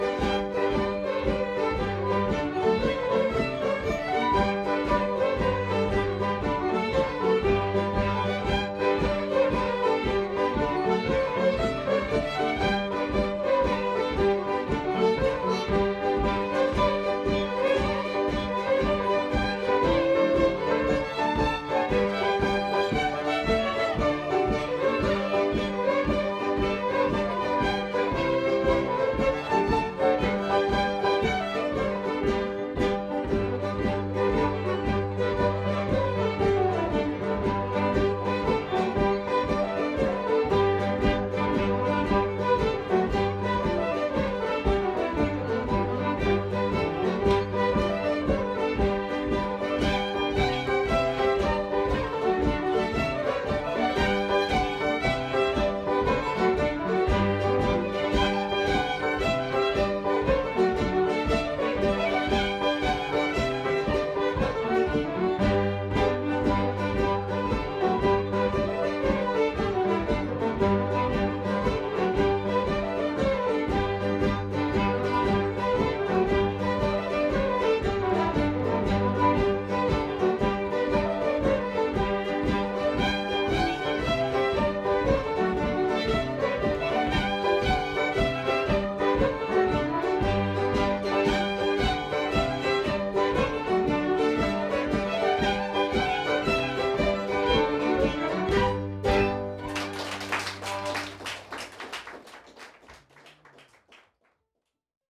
Performer West Prince Fiddlers
pno
mand